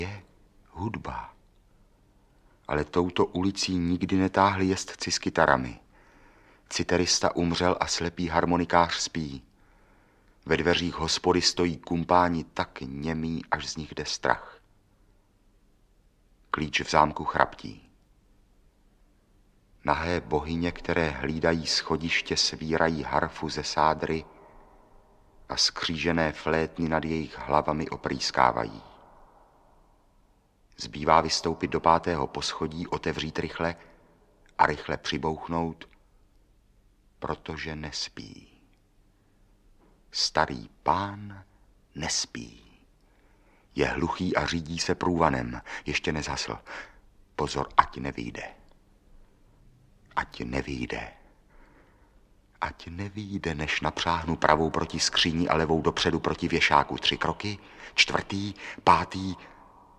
Audiobook
Audiobooks » Short Stories
Read: Růžena Merunková